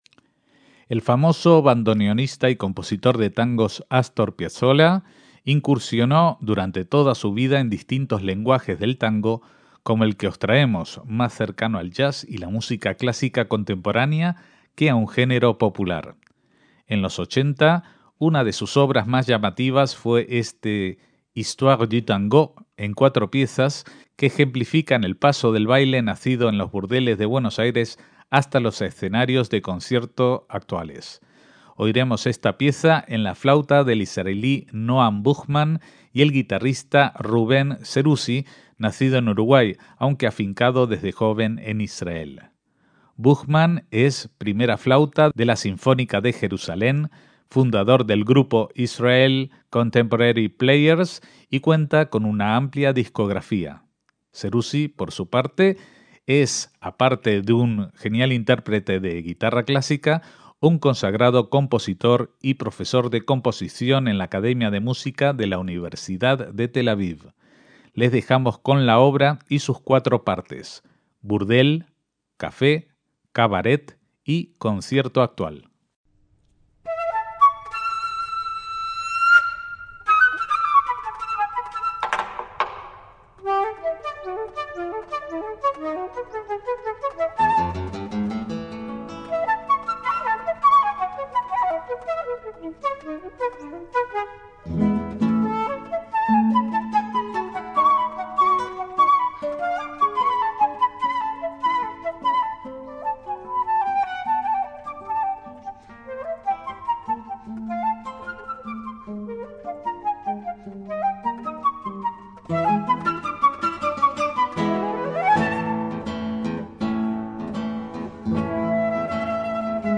flautista
guitarrista